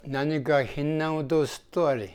Aizu Dialect Database
Type: Statement
Final intonation: Falling
Location: Showamura/昭和村
Sex: Male